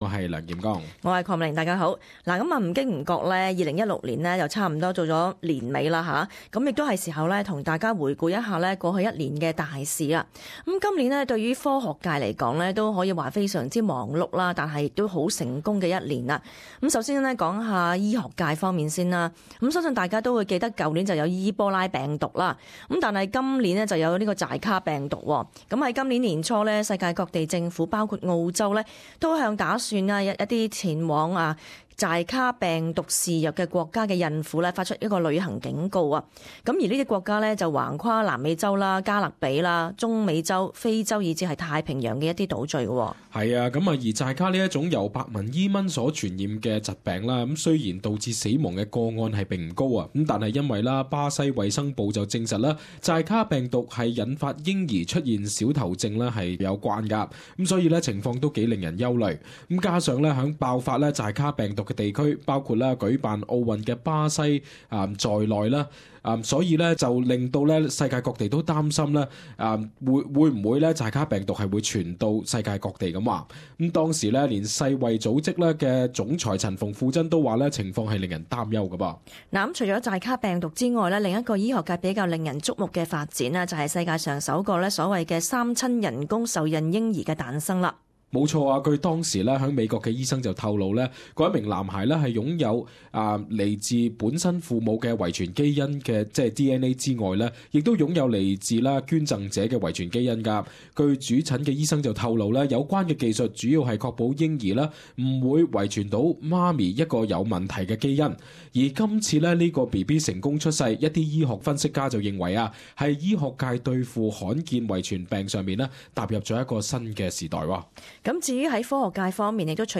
【時事報導】回顧2016年科學界多項新突破